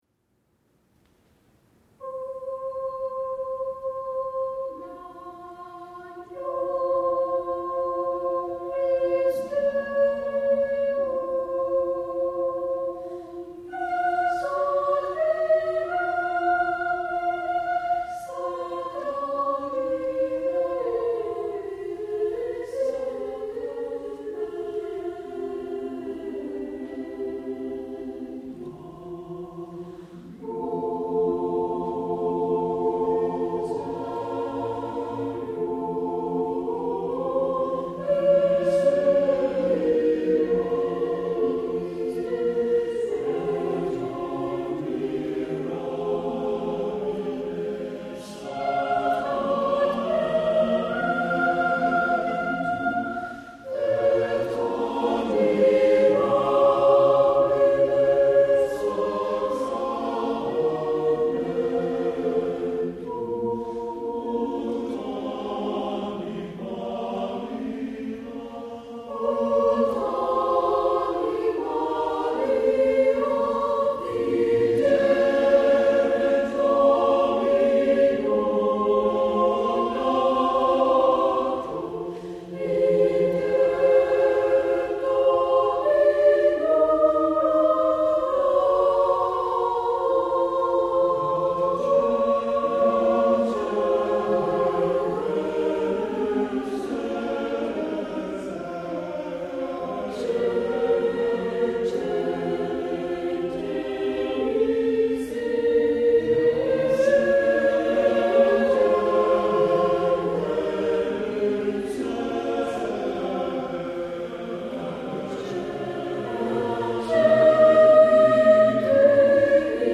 Repertoir - AUP Indonesian Chorale
Note: Sound quality may differ from original recording.